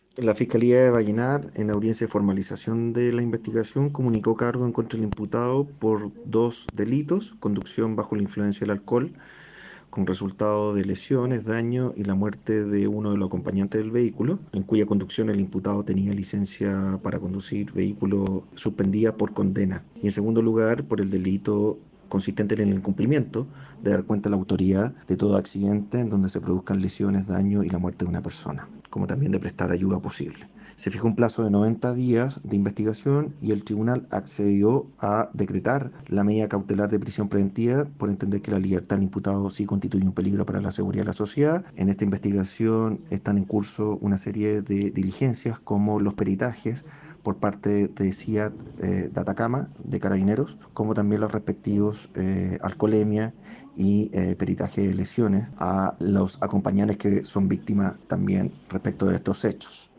CUÑA-FISCAL-NICOLAS-ZOLEZZI-ACCIDENTE.mp3